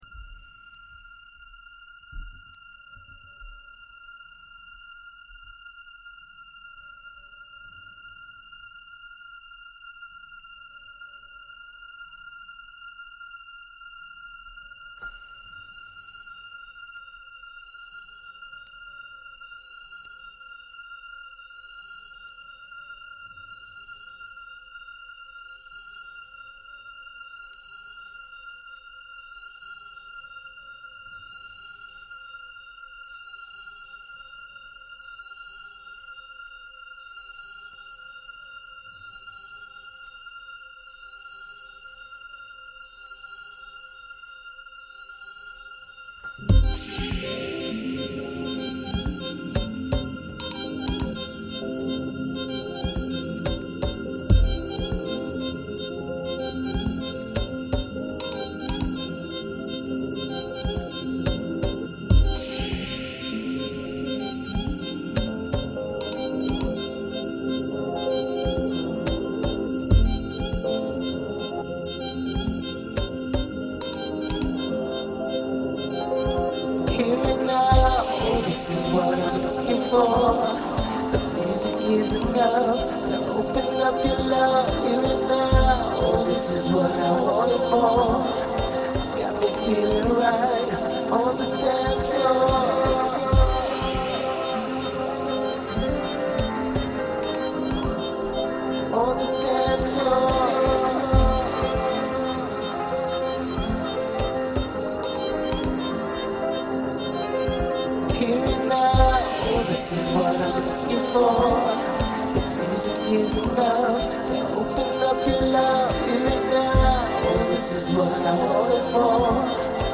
極上ディープ・ソウル・ハウス！
中毒性の高いアシッド風味のナイス・リミックスです！
ニュー・ディスコ～ディスコ・エディットっぽいアプローチの光るディスコ・ハウス